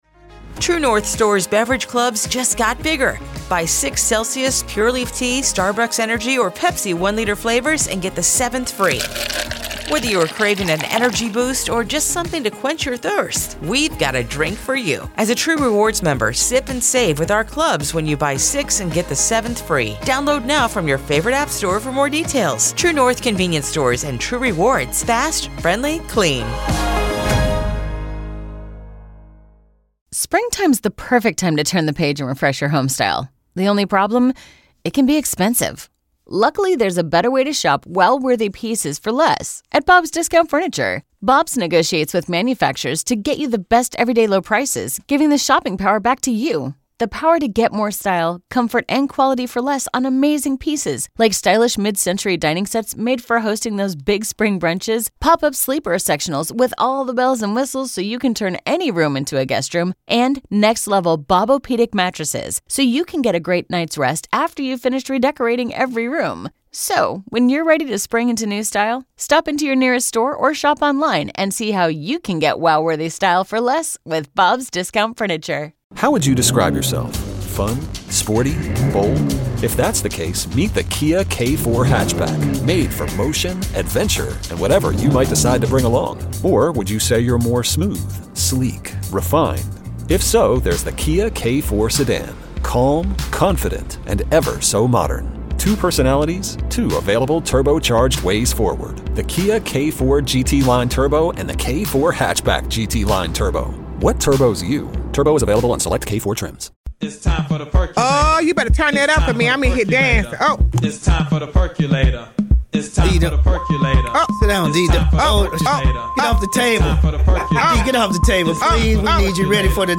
Recently on "The WAOK Morning Show" Guest host Dr. Dee-Dawkins Haigler spoke with prominent political figure and former state representative Vernon Jones about Mr. Jones's unique political journey, particularly his transition from the Democratic to the Republican party.